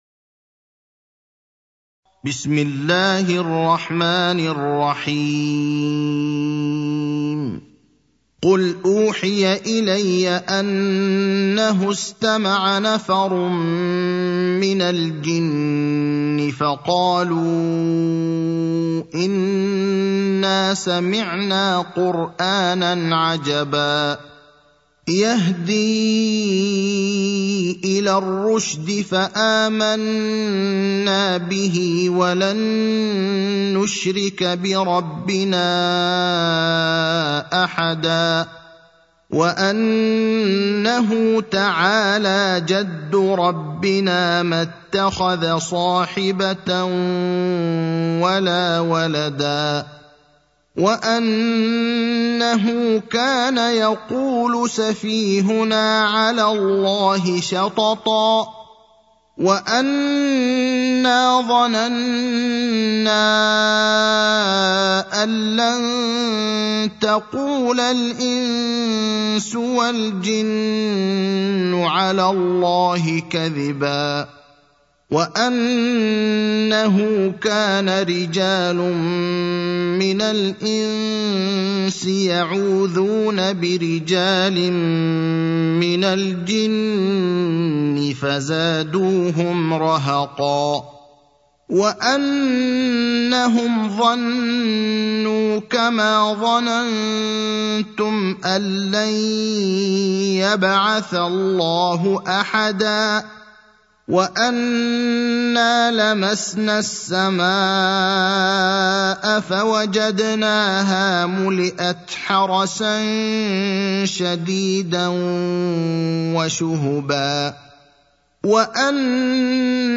المكان: المسجد النبوي الشيخ: فضيلة الشيخ إبراهيم الأخضر فضيلة الشيخ إبراهيم الأخضر الجن (72)